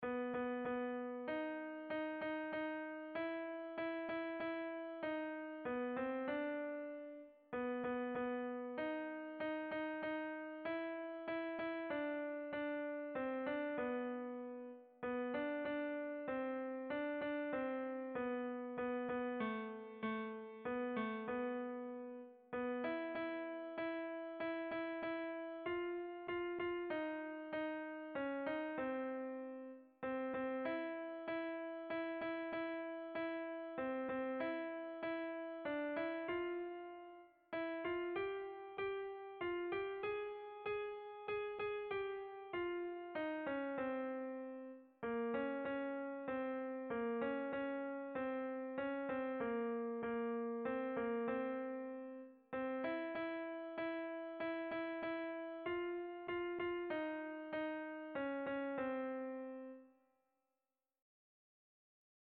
Sentimenduzkoa
Lleida eta Tarragona inguruetako emakume taldeek santu batekin etxez etxe kantari erabiltzen zuten doinu tradizionala.
Hamaseiko ertaina (hg) / Zortzi puntuko ertaina (ip)
A1A2BCDE